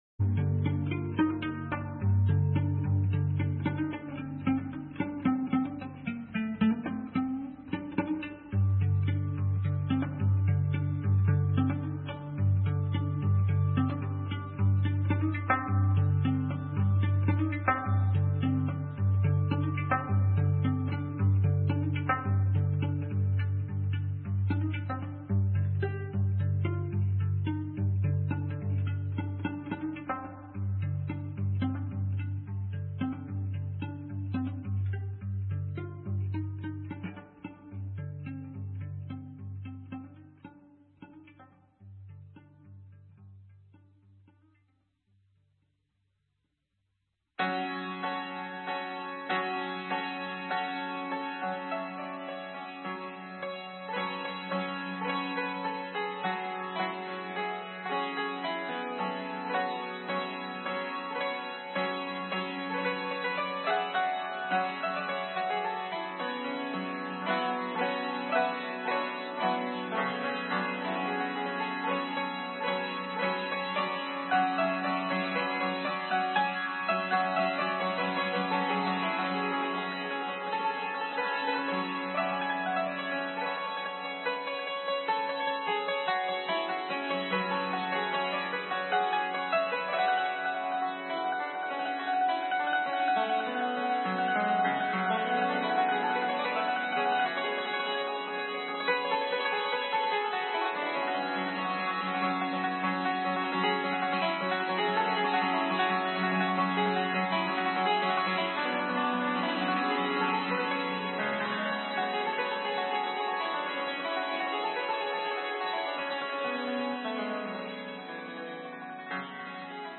Enemy Combatant Radio webcast for sunday 20 april 2003 -- the weekly ECR wrapup show is happening Sunday evenings @ 8pm (tonight's was 9pm to midnight) --------- Tune in to ECR coverage of the Lockheed Martin mass die-in, civilian weapons inspection, and blockade this Tuesday morning starting at 7 am.